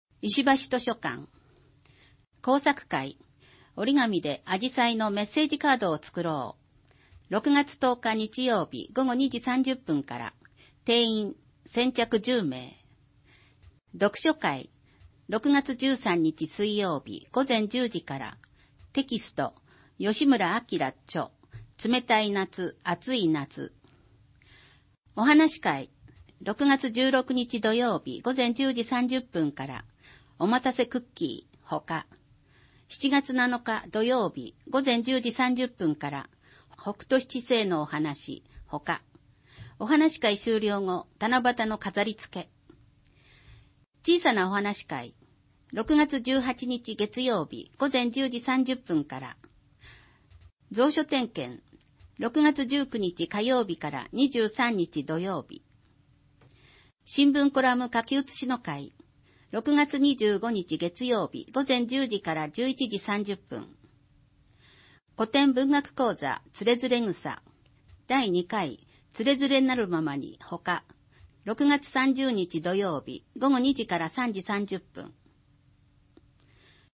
音声ファイルで読み上げられるページ番号はデイジー版（←無償配布を行っています。くわしくはこちらをクリックしてください。）用となっております。